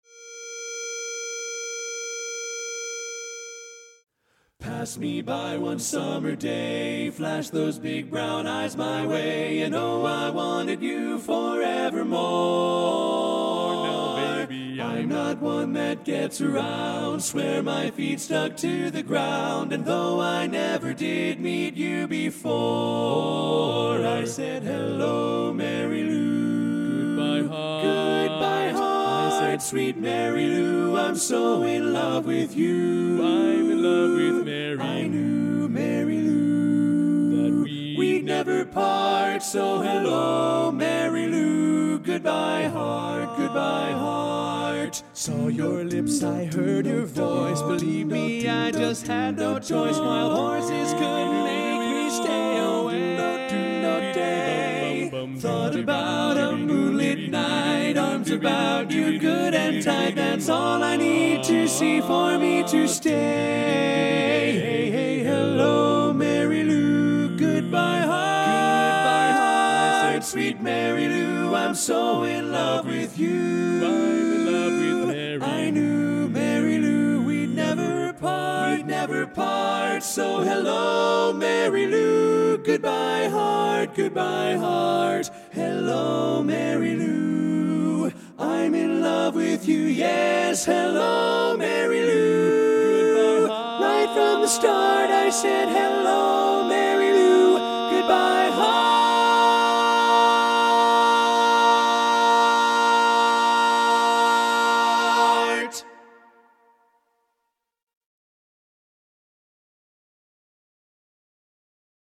Kanawha Kordsmen (chorus)
Up-tempo
B♭ Major
Tenor